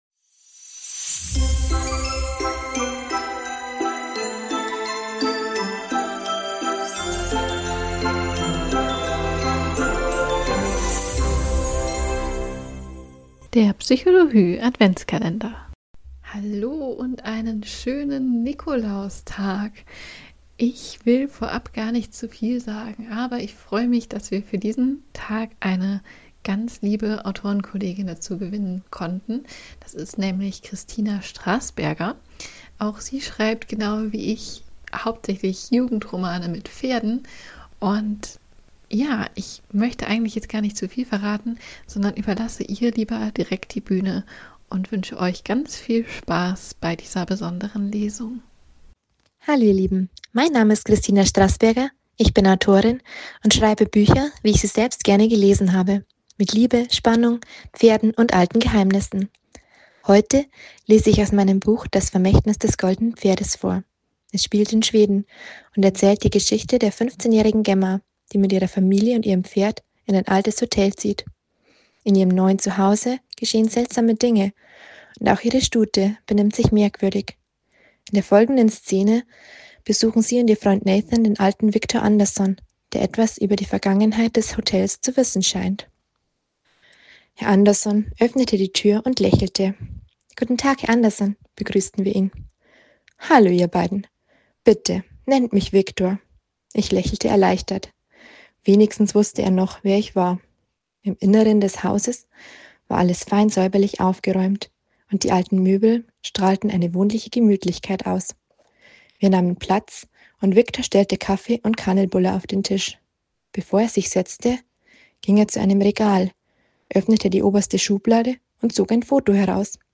Lesung